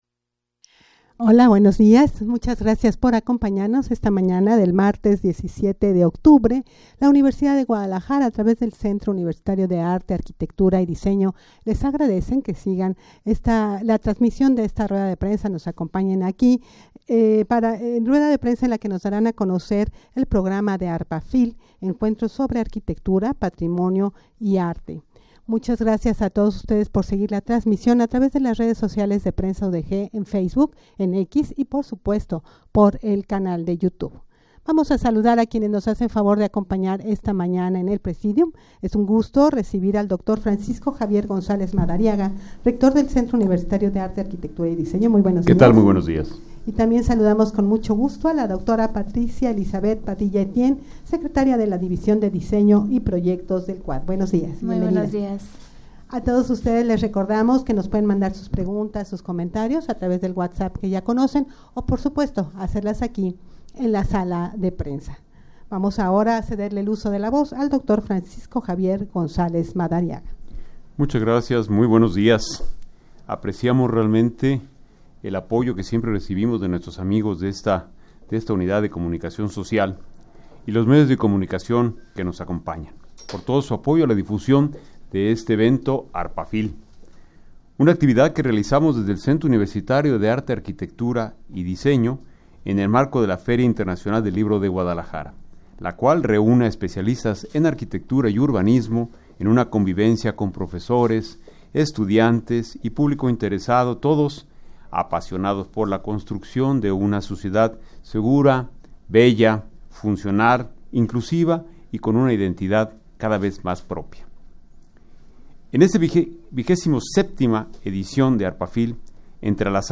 Audio de la Rueda de Prensa
rueda-de-prensa-para-dar-a-conocer-el-programa-de-arpafil-encuentro-sobre-arquitectura-patrimonio-y-arte-en-general.mp3